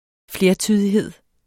Udtale [ ˈfleɐ̯ˌtyðiˌheðˀ ]